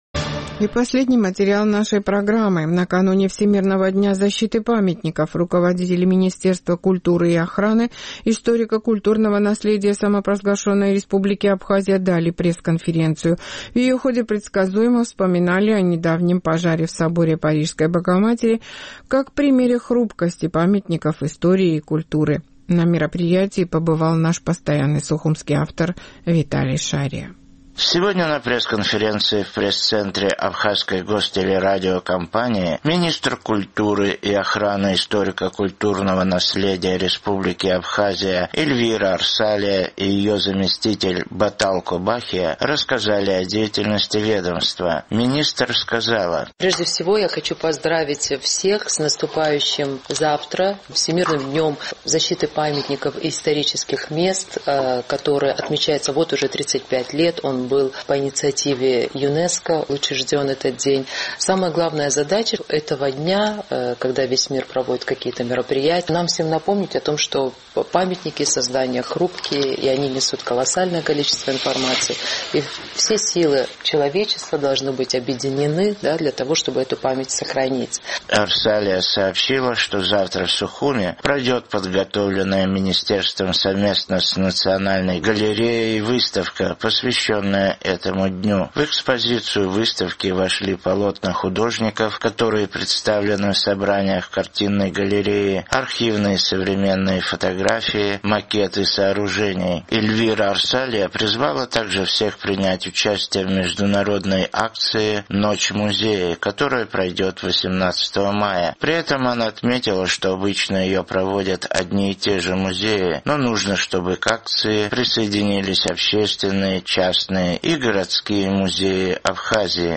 Сегодня на пресс-конференции в пресс-центре Абхазской гостелерадиокомпании министр культуры и охраны историко-культурного наследия РА Эльвира Арсалия и ее заместитель Батал Кобахия рассказали о деятельности ведомства.